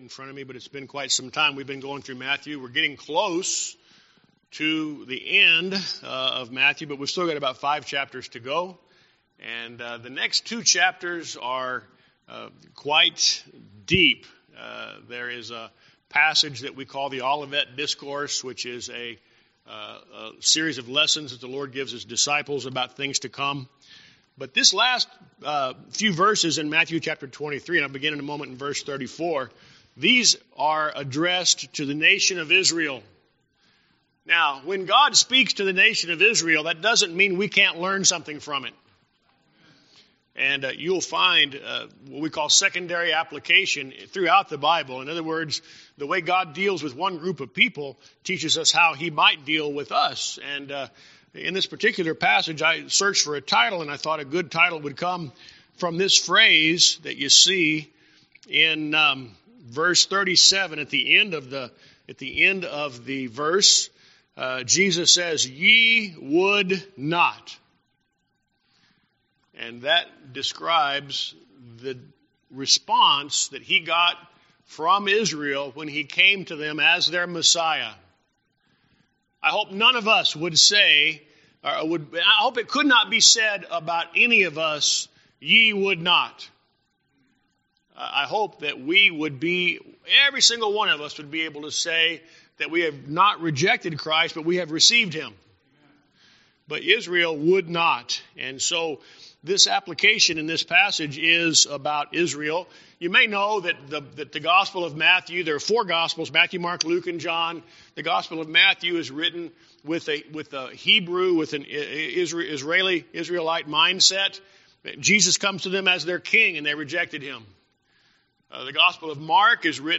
Sermons
Guest Speaker